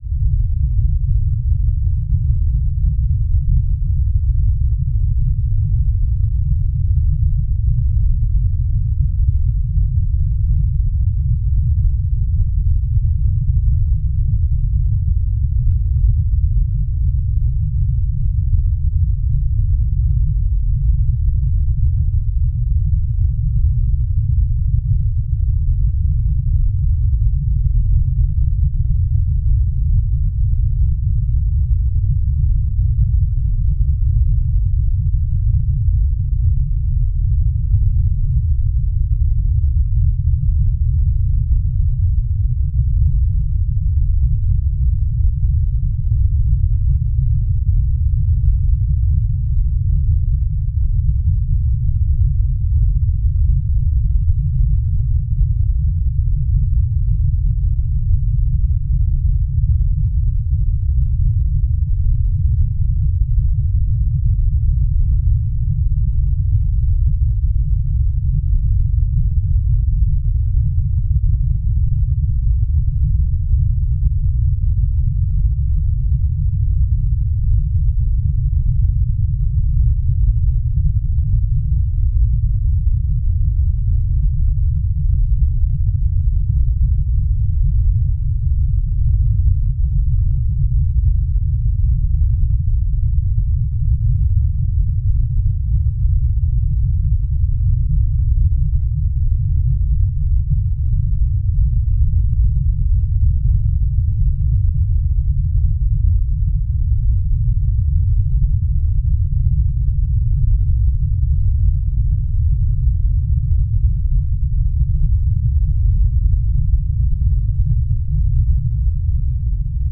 На этой странице собраны звуки, которые ассоциируются с концом света: от далеких взрывов и гула сирен до хаотичного шума разрушающегося мира.
Звук вибрации земли в момент конца света гул